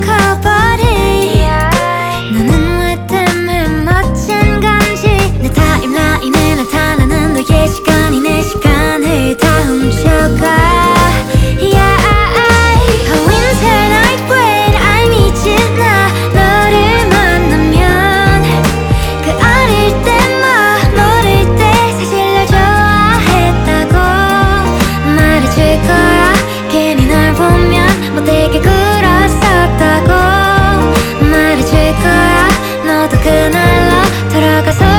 Жанр: Фолк-рок